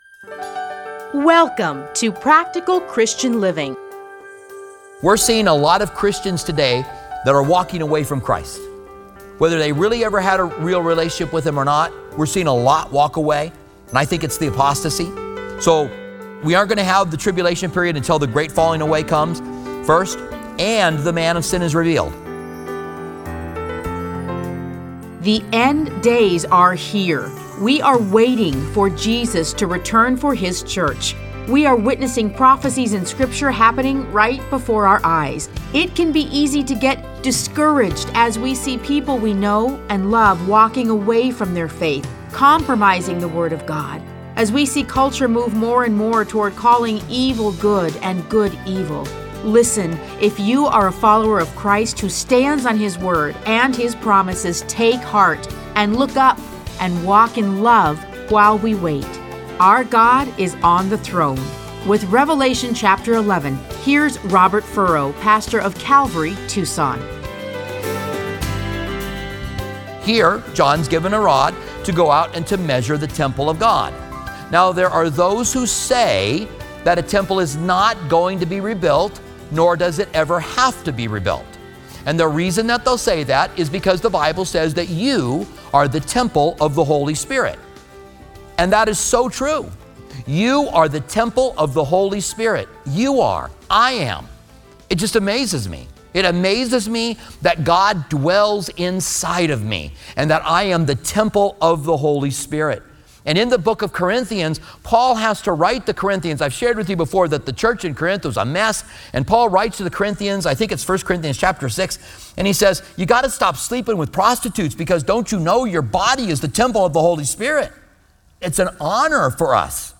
Listen to a teaching from Revelation 11:1-2.